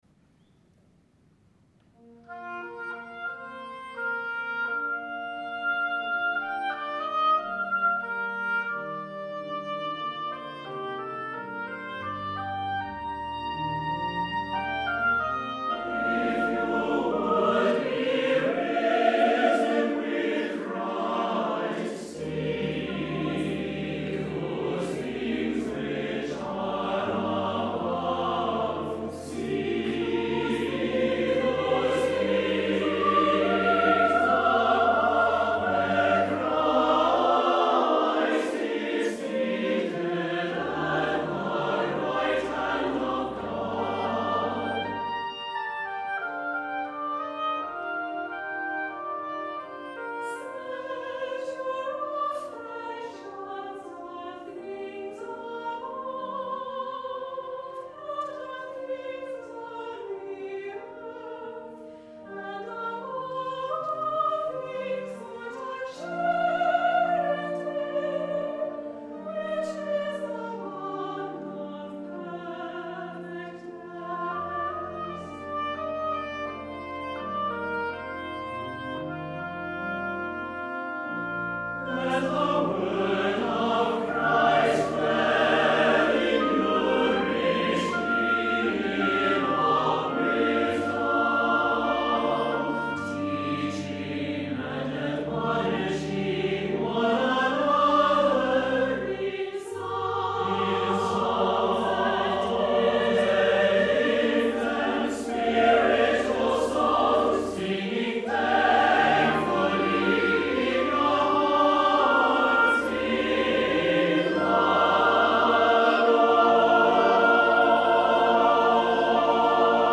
moving praise anthem with oboe obligato, based on scripture
quiet central section for sopranos alone
rare opportunity for woodwind and voice
lovely, simple oboe part